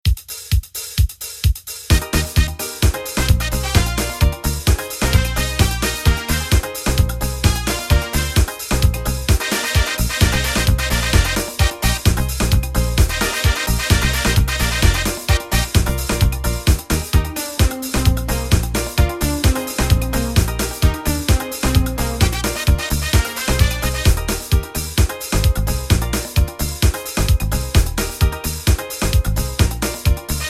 Soca/Calypso